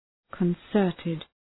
Προφορά
{kən’sɜ:rtıd}